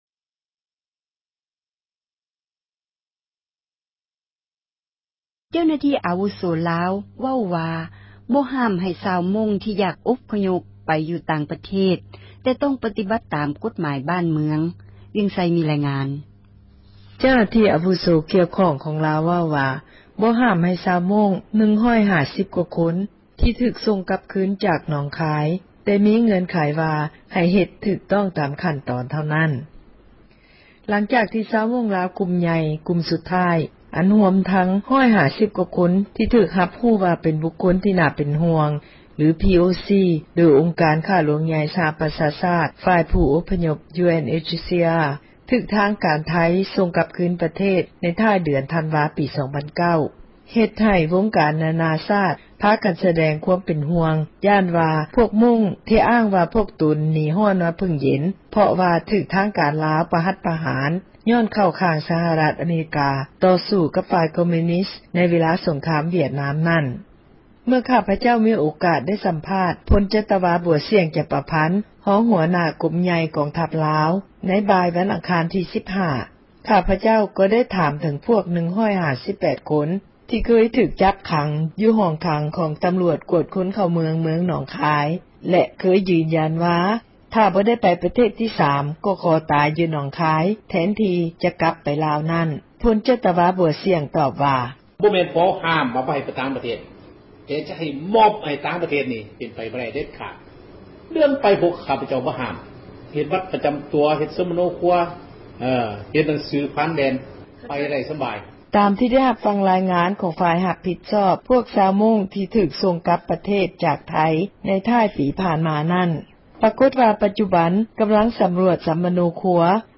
ມີລາຍງານ ຈາກນະຄອນຫຼວງ ວຽງຈັນ.